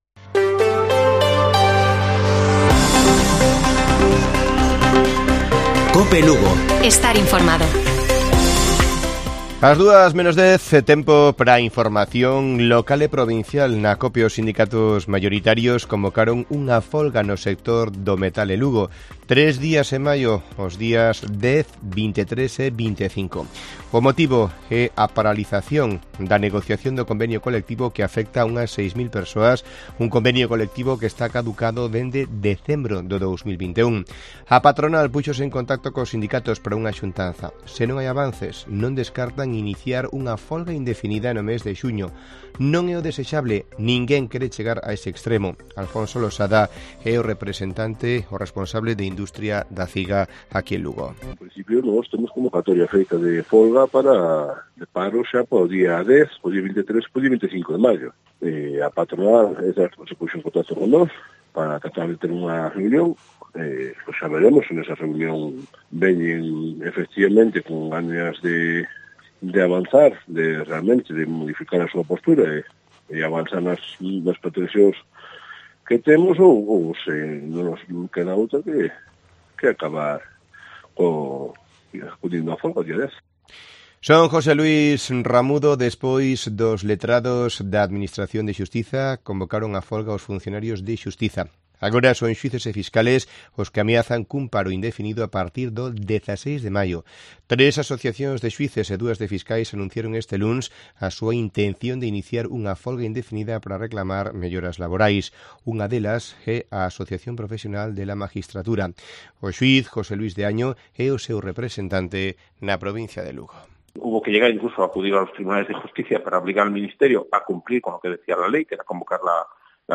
Informativo Mediodía de Cope Lugo. 24 de abril 13:50 horas